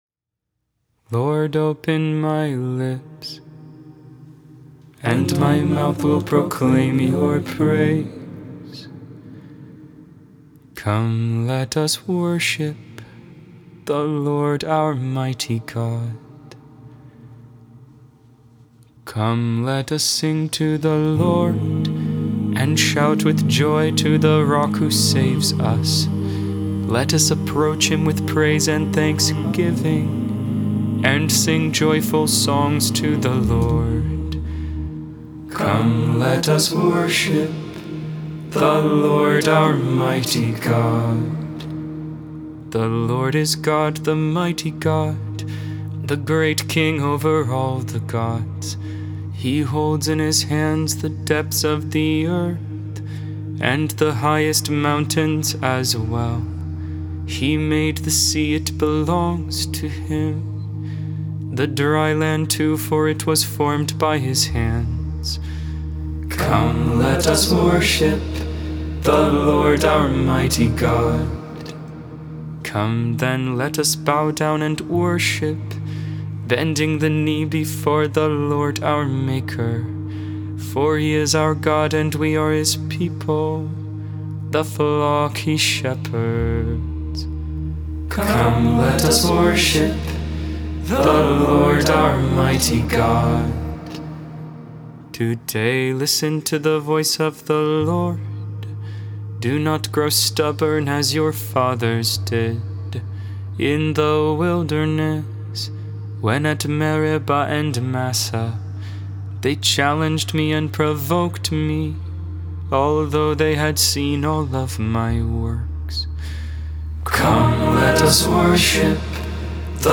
Deus in Adjutorium Hymn: Kontakion (tone #1) and Oikos from Greek Matins of St. Anthony.